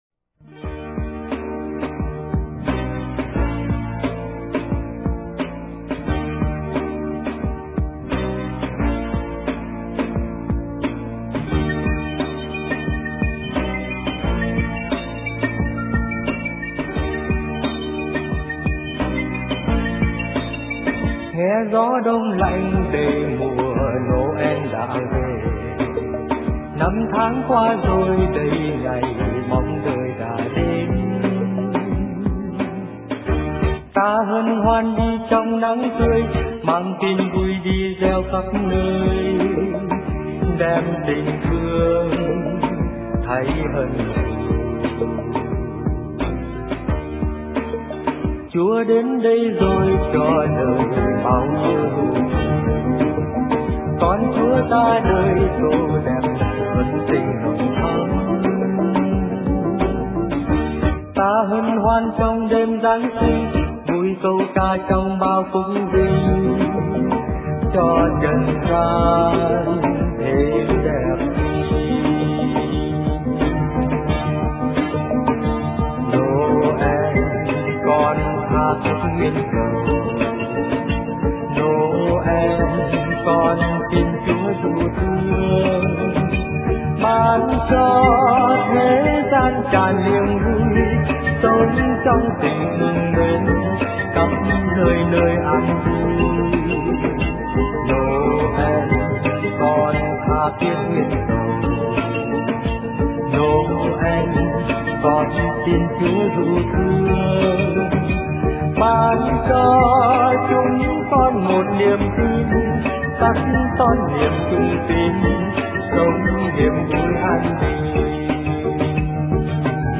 * Thể loại: Giáng Sinh